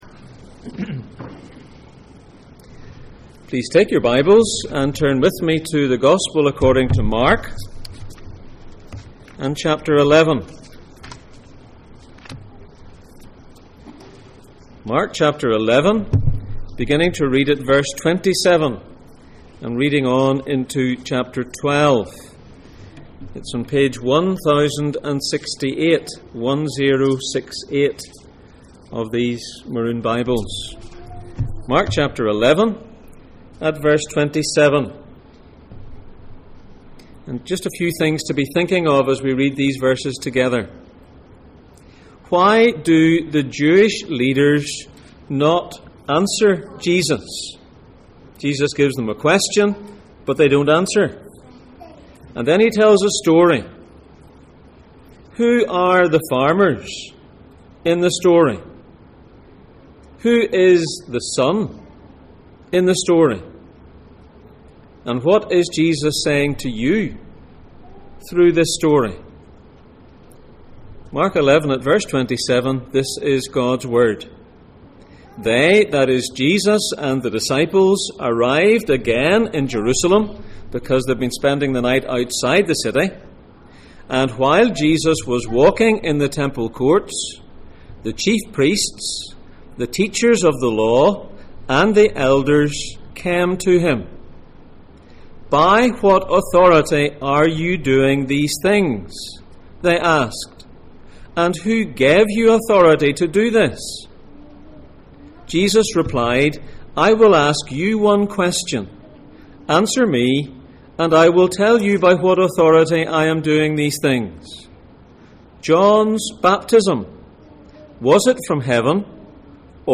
Jesus in Mark Passage: Mark 11:27-12:12, Mark 1:7, Matthew 21:44 Service Type: Sunday Morning